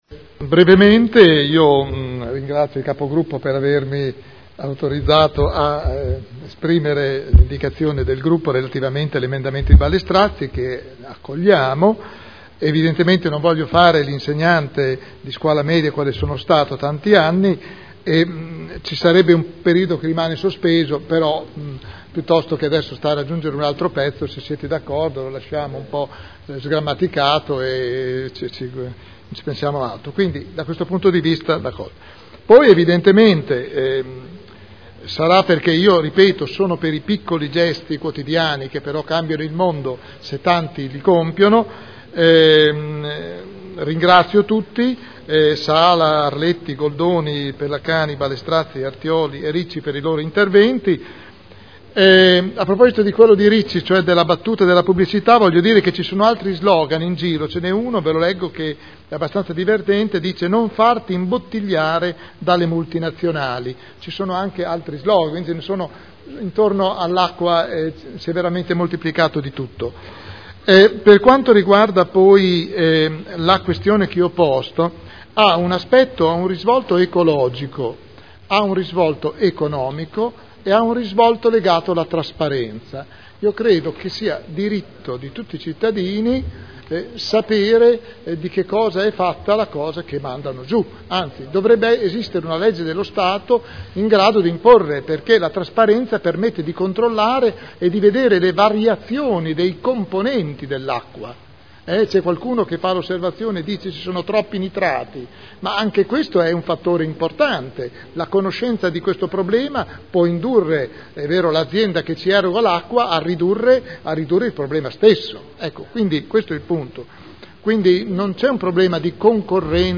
William Garagnani — Sito Audio Consiglio Comunale